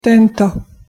pronunciation_sk_tento.mp3